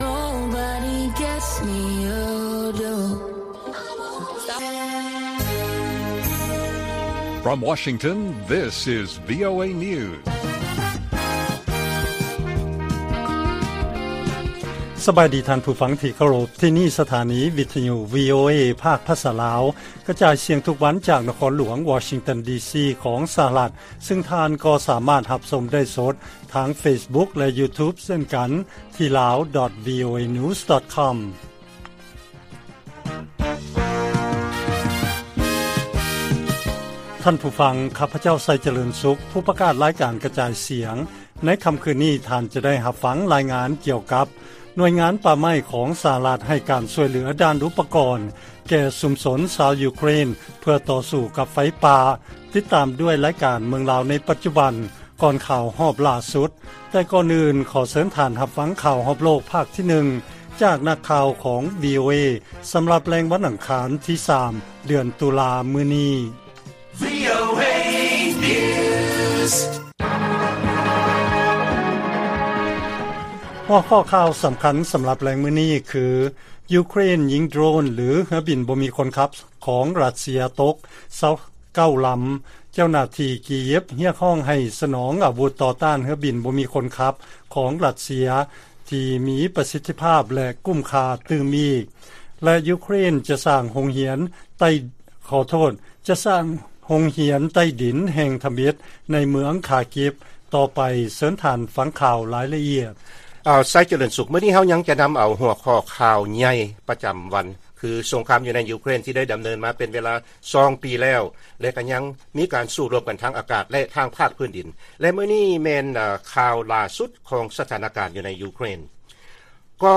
ລາຍການກະຈາຍສຽງຂອງວີໂອເອ ລາວ: ຢູເຄຣນ ຍິງໂດຣນ ຫຼືເຮືອບິນບໍ່ມີຄົນຂັບ ຂອງຣັດເຊຍ ຕົກ 29 ລຳ